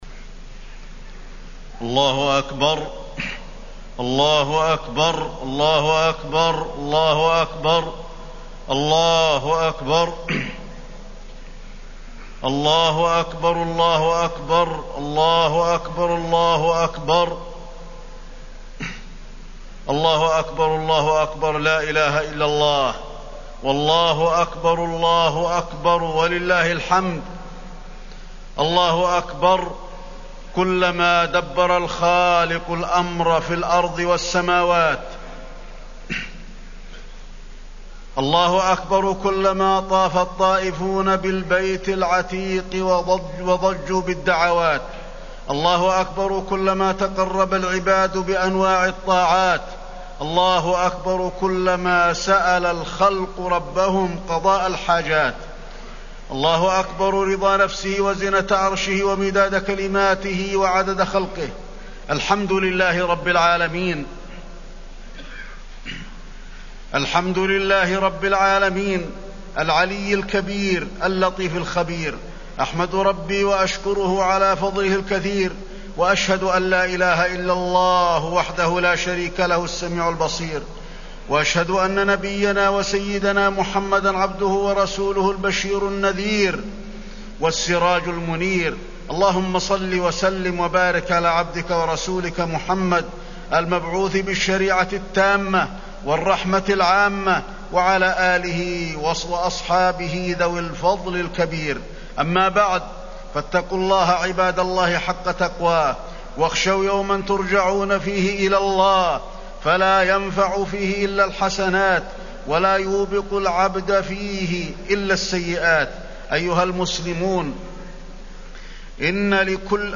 خطبة عيد الأضحى - المدينة - الشيخ علي الحذيفي
المكان: المسجد النبوي